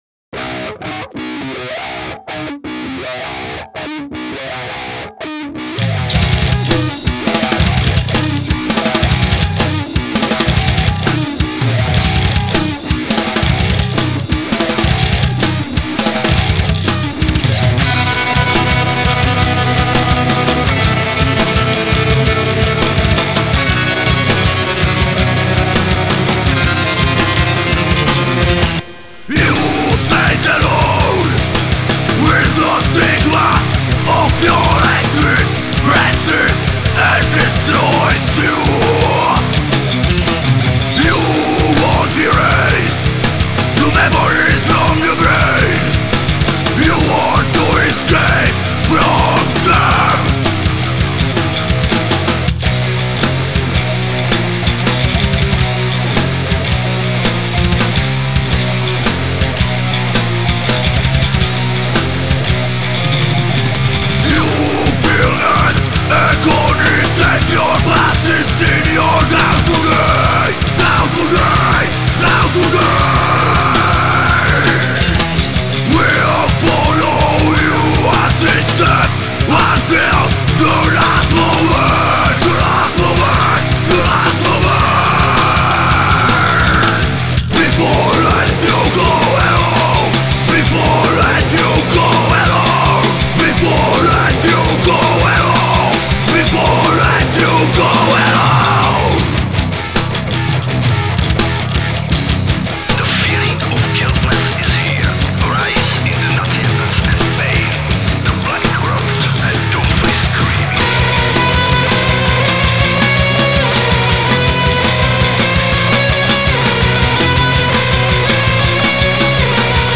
Hard & Heavy